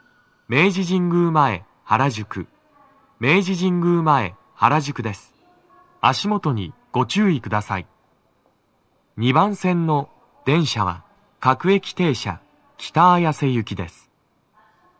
足元注意喚起放送と、乗り換え放送が付帯するため、フルの難易度は高いです
男声
到着放送1